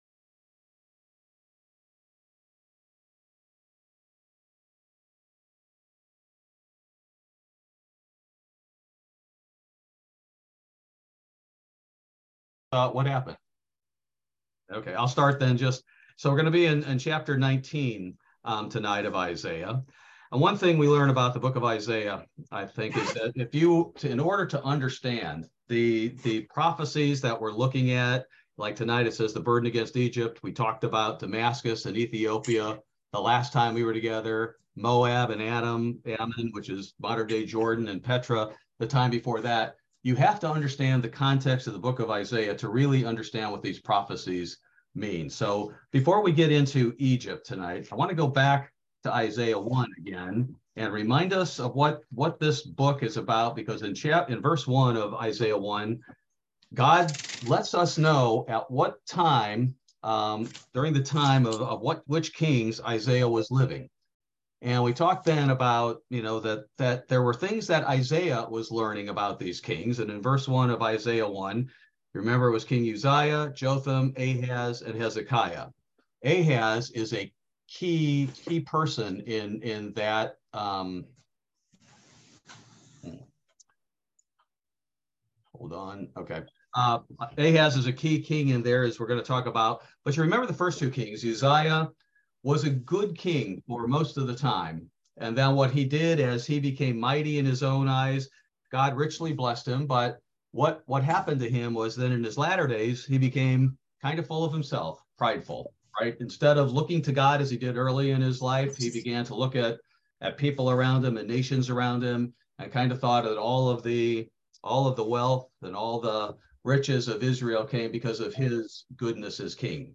Bible Study: December 14, 2022